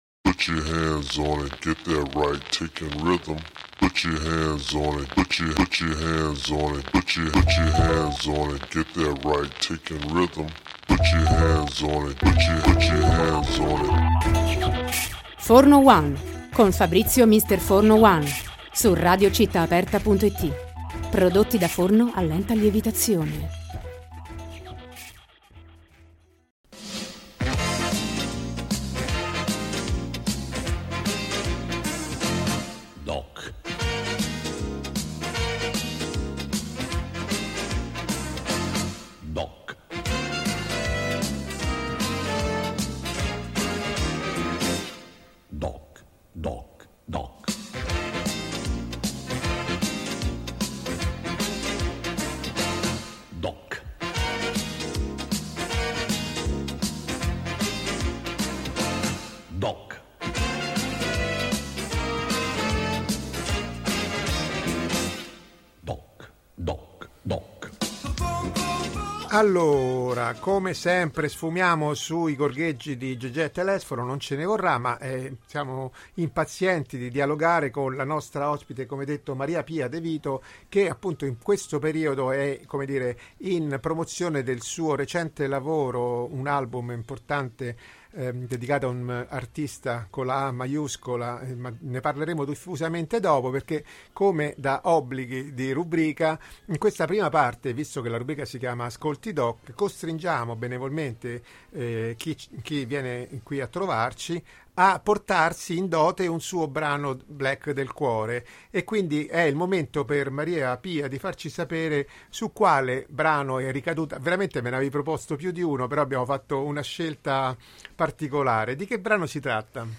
Intervista-MPDeVito.mp3